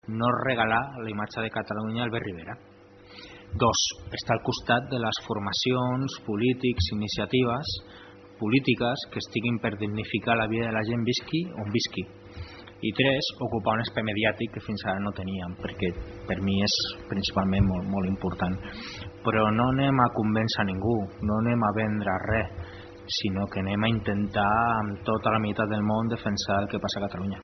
En declaracions a aquesta emissora, Rufián ha continuat mostrant-se partidari del Referèndum Unilateral d’Independència “sota la legalitat catalana”, que redueix el procés a un exercici democràtic.
Rufián responia.